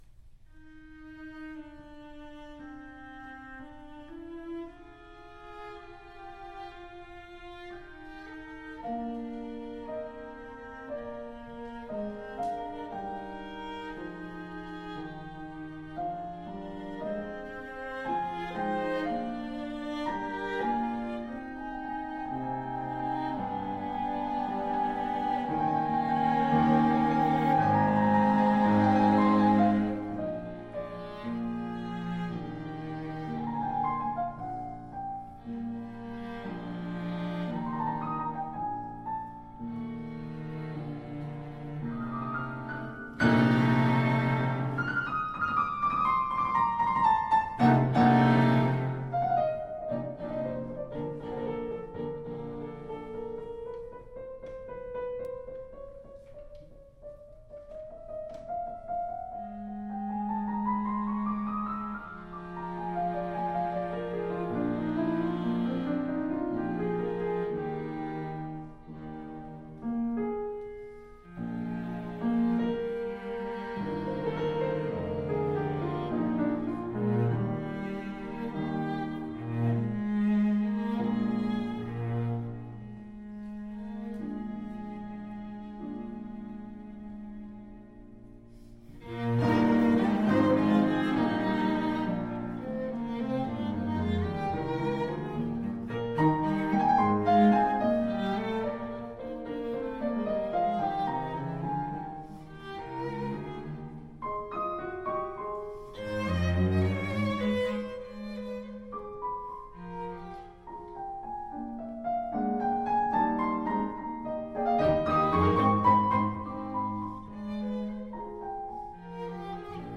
Instrument: Piano Trio
Style: Classical
Audio: Boston - Isabella Stewart Gardner Museum
violin
cello